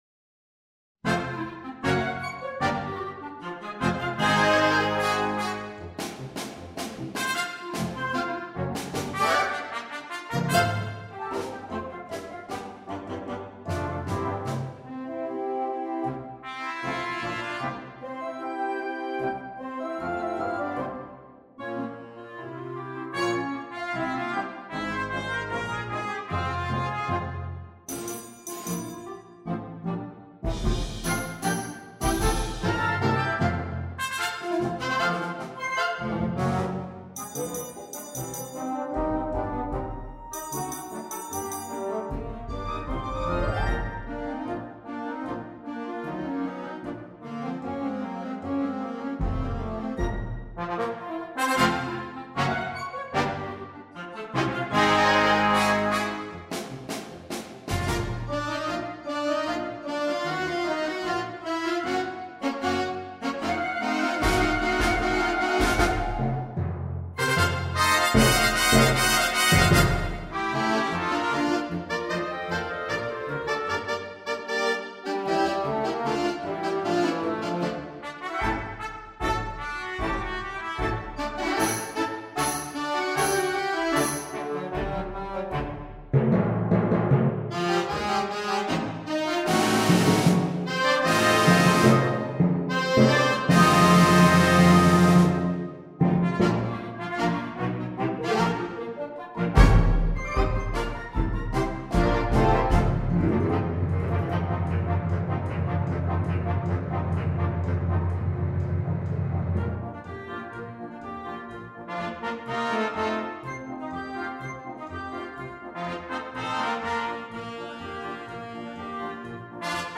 for band